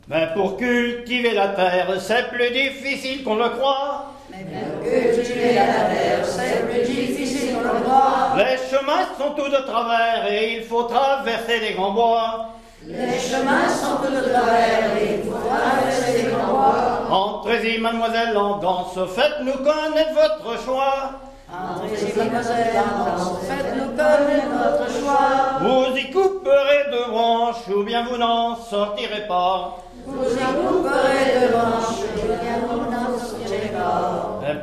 ronde à embrasser
Genre strophique
Pièce musicale inédite